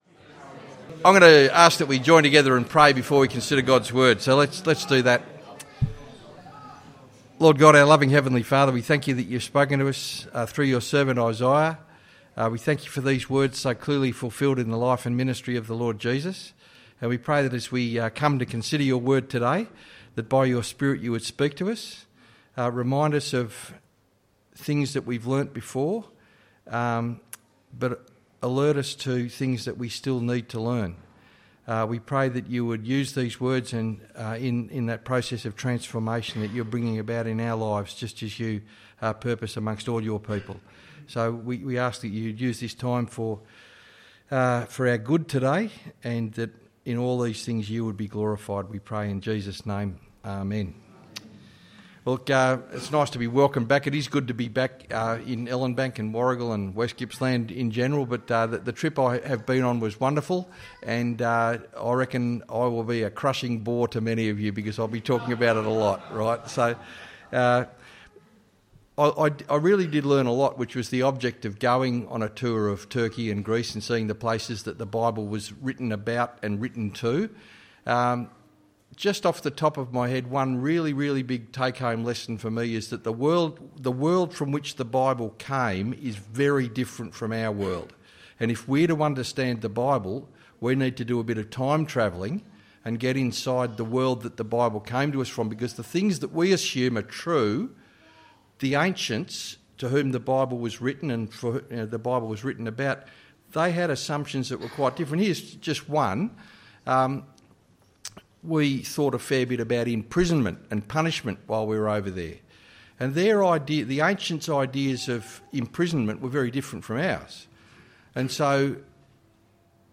Sermon: Isaiah 66:1-24